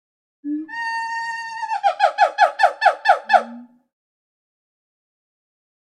Звук голоса гиббона